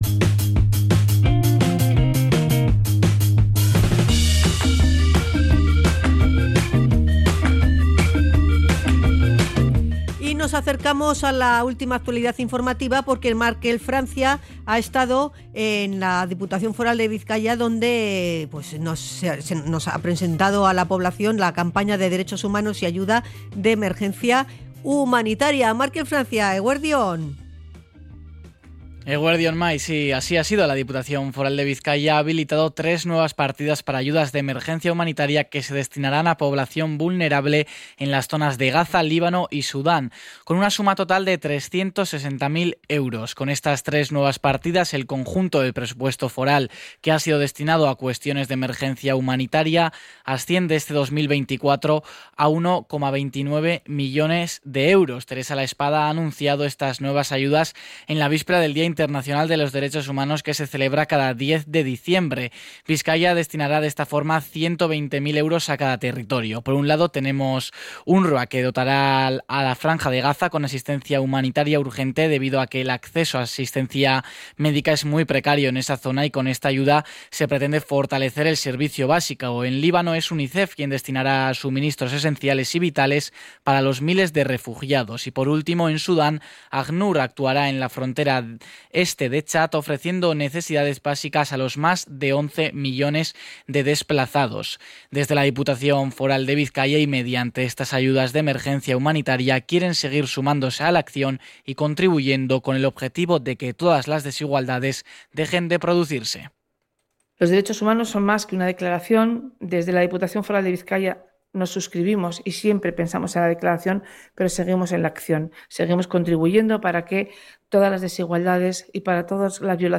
Cronica-Derechos-humanos.mp3